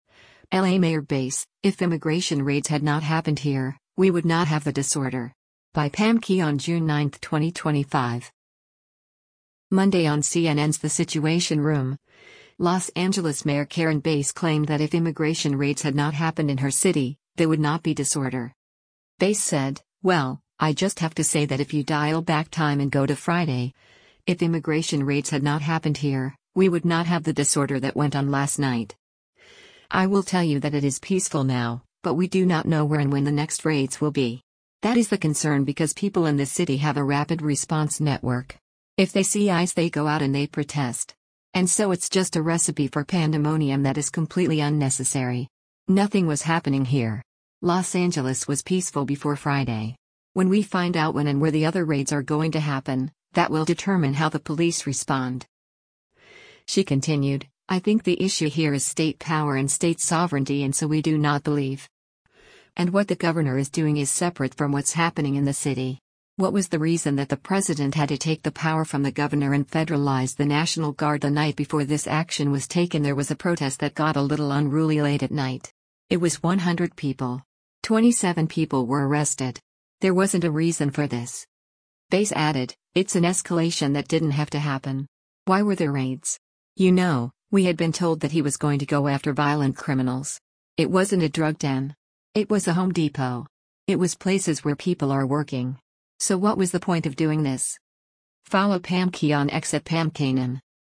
Monday on CNN’s “The Situation Room,” Los Angeles Mayor Karen Bass claimed that if immigration raids had not happened in her city, there would not be “disorder.”